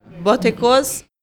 prononciation
Botecos-pron.mp3